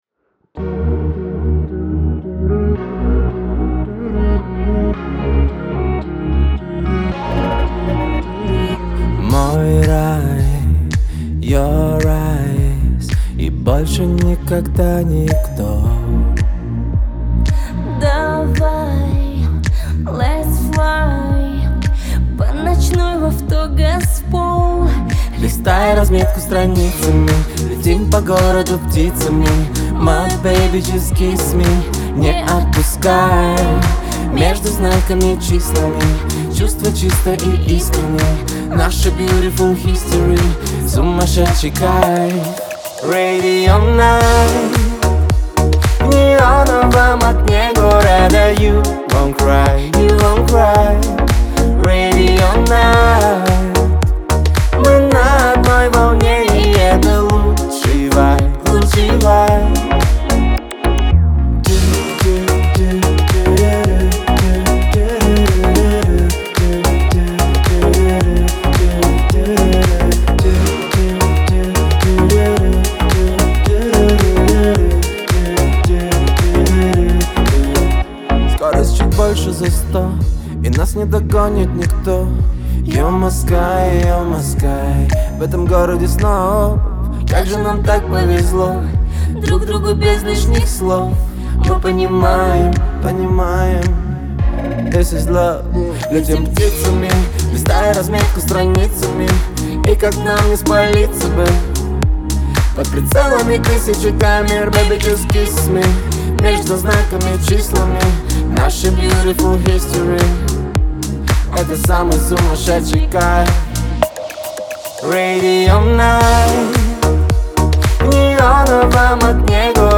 это энергичная поп-песня в жанре электропоп
Сочетание мощного вокала и современного звучания